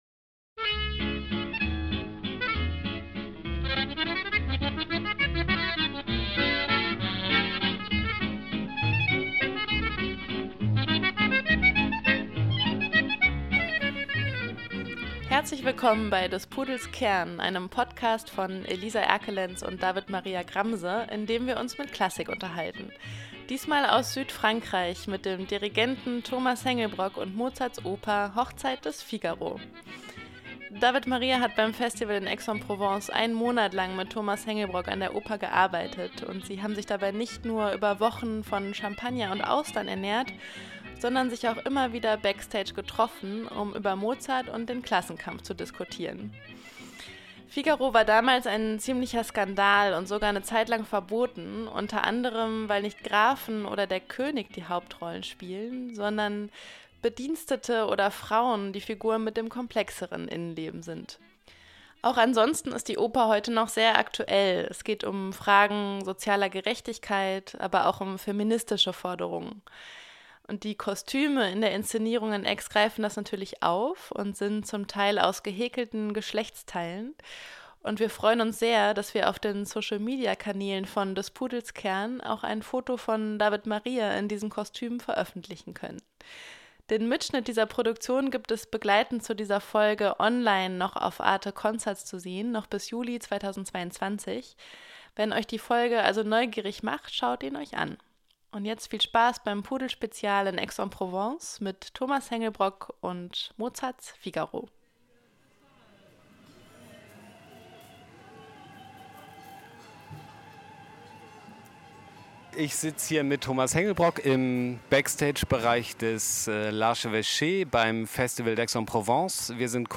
Ein Gruß aus Südfrankreich: Zwischen Austern und Champagner haben wir den Dirigenten Thomas Hengelbrock beim Festival in Aix en Provence jeden Tag zu Mozart befragt.